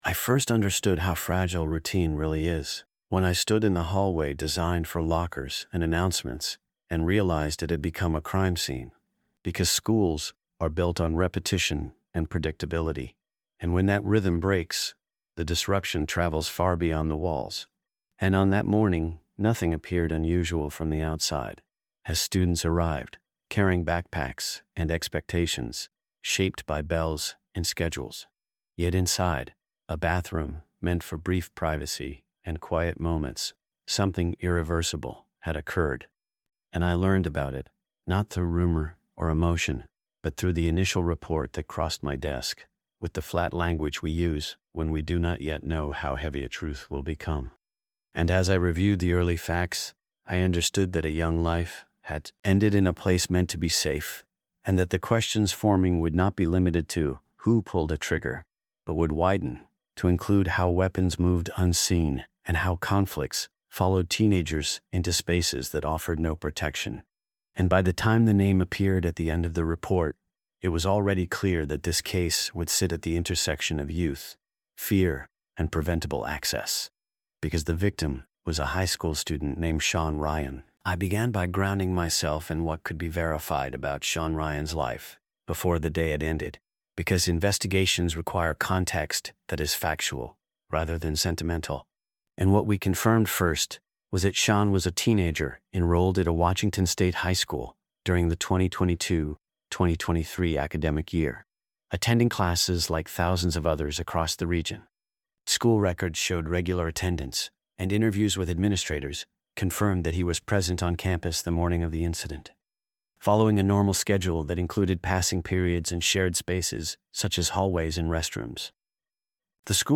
Told from the perspective of a first-person detective narrator, the story reconstructs the case using verified records, surveillance review, and official findings, tracing how an ordinary morning unfolded into a fatal crime. The narrative documents the immediate response, the identification of a student suspect, and the recovery of an unlawfully obtained firearm, while avoiding speculation or dramatization. Grounded strictly in evidence, the episode situates the homicide within the realities of school safety procedures, access failures, and the legal processes that followed.